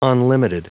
Prononciation du mot unlimited en anglais (fichier audio)
Prononciation du mot : unlimited